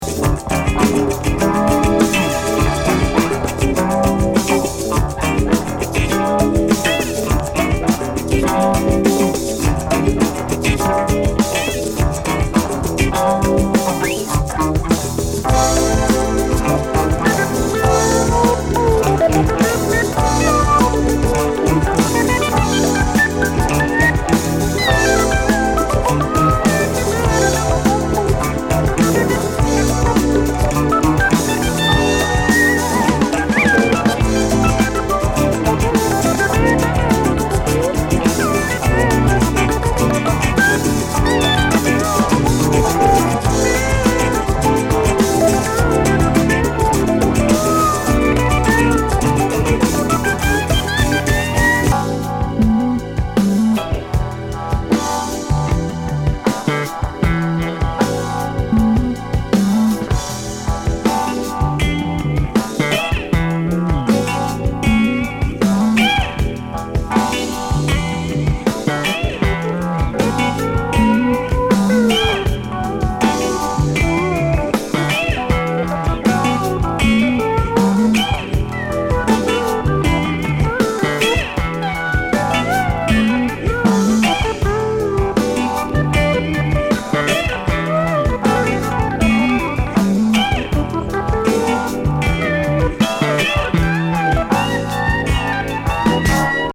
ローズ・ロッキン芳醇グルーブ